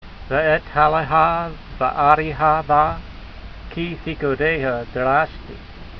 Note: ve-et-hal-le-hah (kaph pronounced ha [soft]), whereas in the third word, kee, it is pronounced hard because of the dagesh.